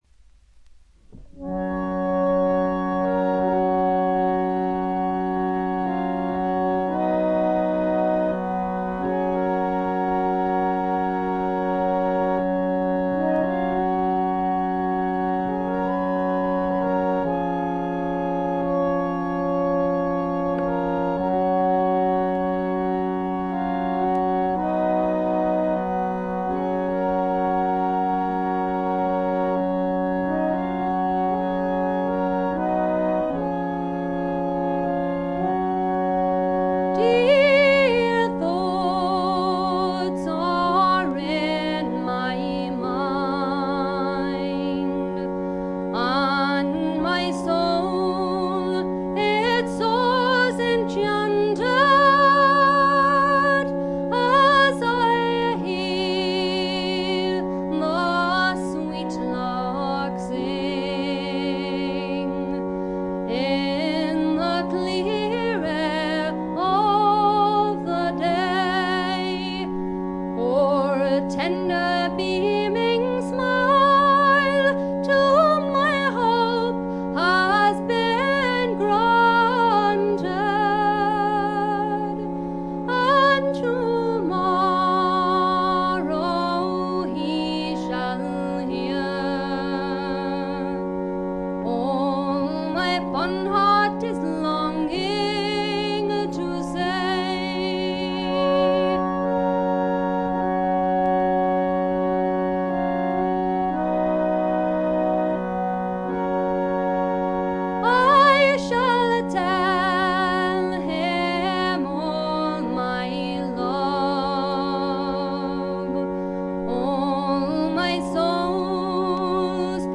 特にオルガンのひなびた音色とかたまらんものがあります。
あまりトラッド臭さがなくほとんどドリーミーフォークを聴いているような感覚にさせてくれる美しい作品です。
試聴曲は現品からの取り込み音源です。
Fiddle, Recorder, Vocals